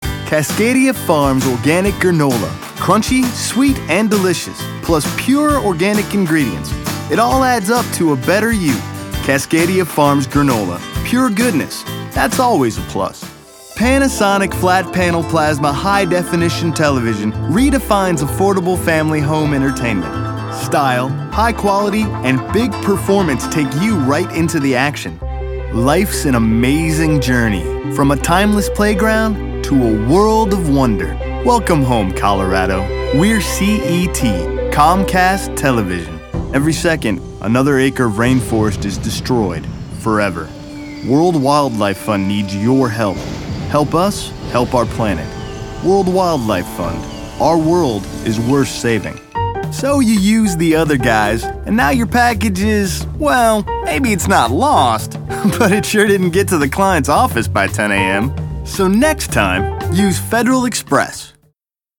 Laid back, guy next door, energetic, believable
middle west
Sprechprobe: Werbung (Muttersprache):